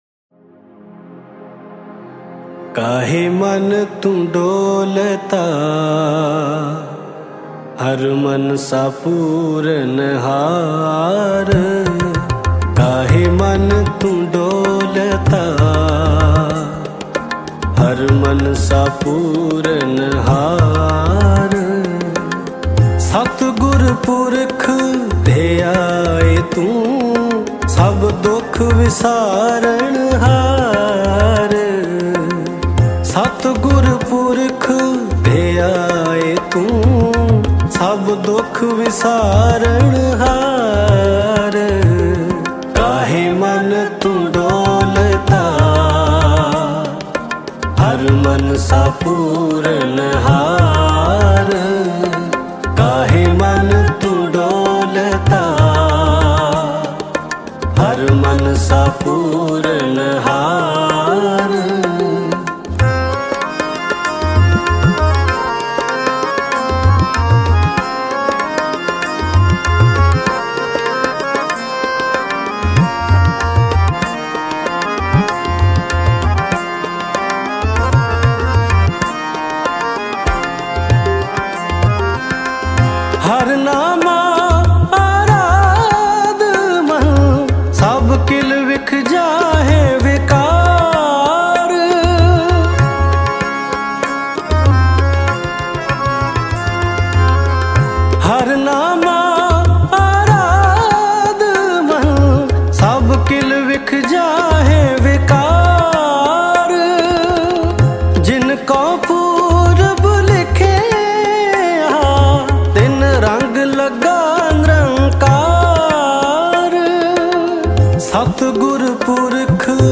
Mp3 Files / Gurbani Kirtan / 2025-Shabad Kirtan / Albums /